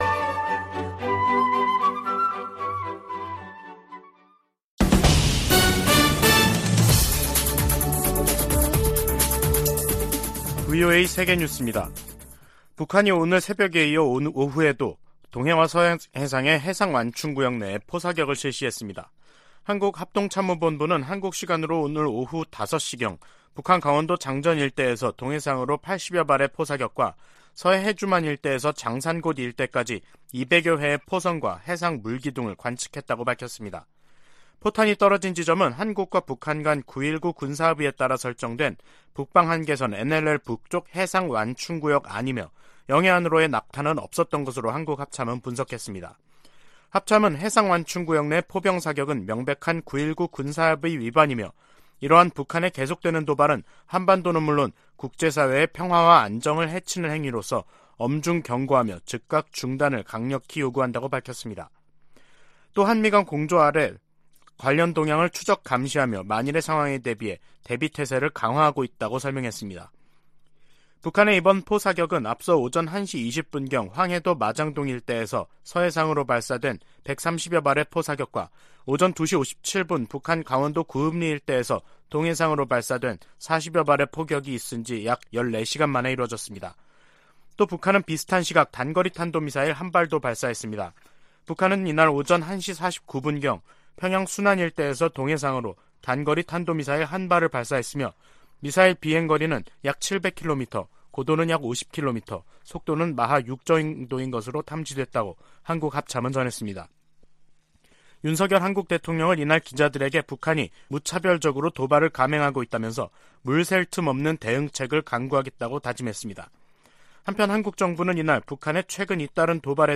VOA 한국어 간판 뉴스 프로그램 '뉴스 투데이', 2022년 10월 14일 2부 방송입니다. 북한이 포 사격을 포함해 군용기 위협 비행, 탄도미사일 발사 등 무차별 심야 도발을 벌였습니다. 한국 정부가 북한의 노골화되는 전술핵 위협에 대응해, 5년 만에 대북 독자 제재에 나섰습니다. 미국이 로널드 레이건 항모강습단을 동원해 한국·일본과 실시한 연합훈련은 역내 안정 도전 세력에 대한 단합된 결의를 보여준다고 미 7함대가 밝혔습니다.